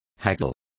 Complete with pronunciation of the translation of haggles.